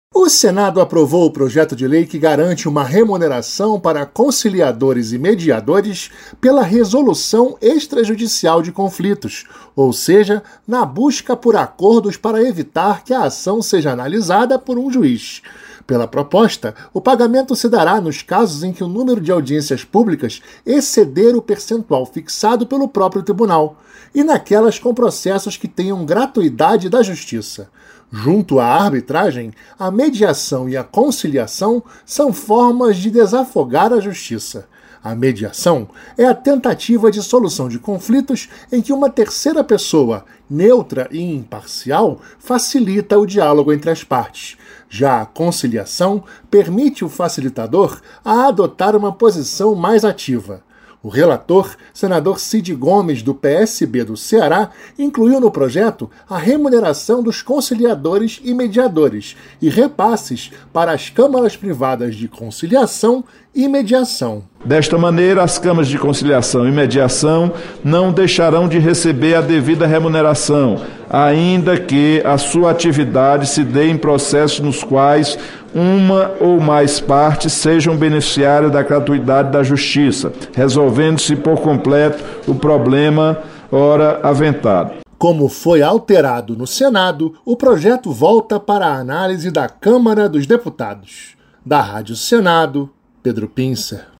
2. Notícias